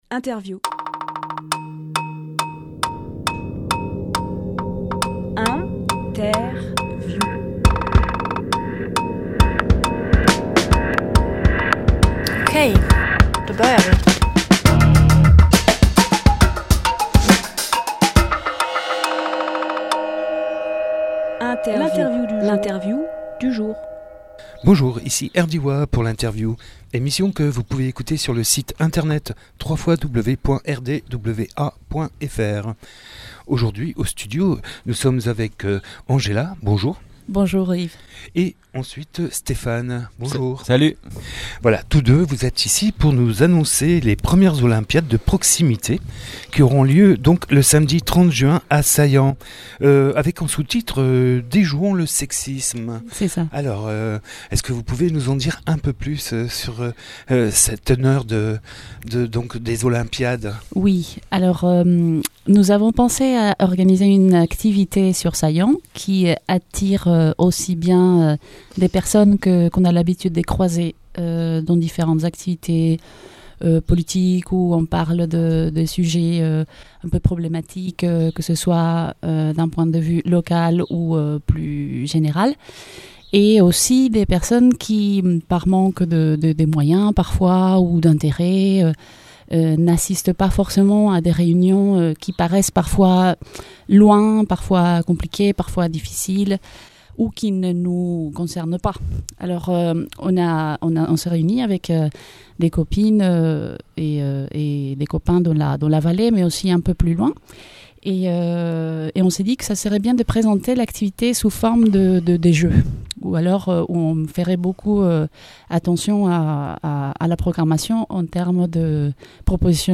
Emission - Interview Les 1ères Olympiades de proximité à Saillans Publié le 22 juin 2018 Partager sur…
Lieu : Studio RDWA